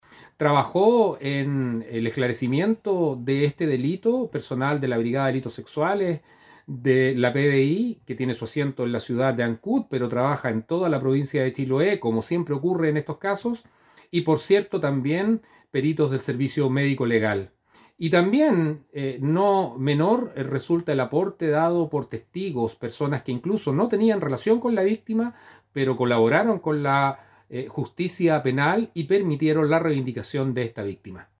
El persecutor de Castro puso de relieve las instituciones y las personas que actuaron en favor de esclarecer el delito cometido por el sujeto.